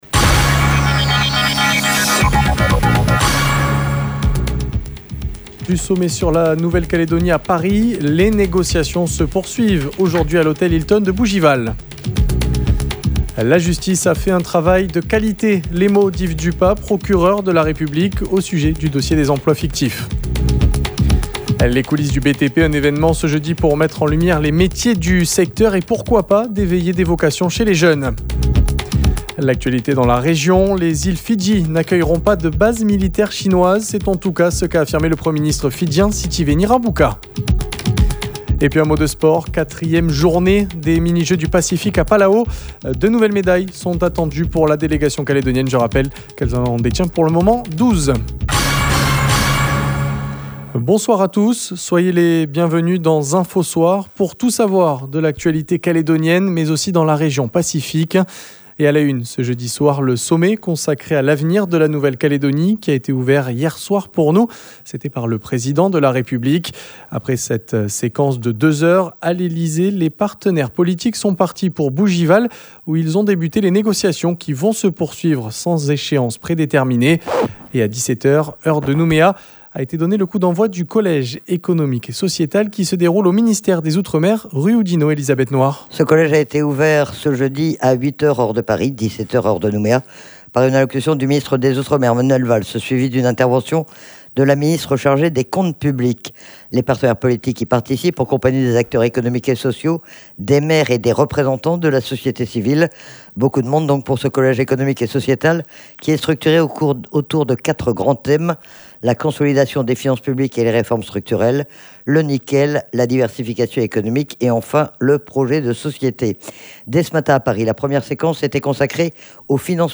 Les mots d’Yves Dupas, Procureur de la République, au sujet du dossier des emplois fictifs